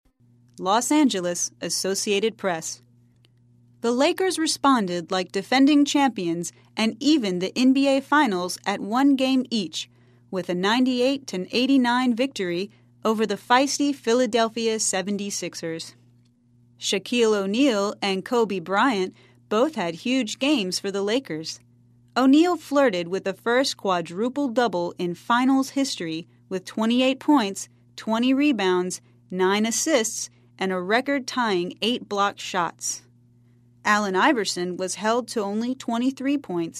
在线英语听力室赖世雄英语新闻听力通 第75期:湖人队的冠军姿态的听力文件下载,本栏目网络全球各类趣味新闻，并为大家提供原声朗读与对应双语字幕，篇幅虽然精短，词汇量却足够丰富，是各层次英语学习者学习实用听力、口语的精品资源。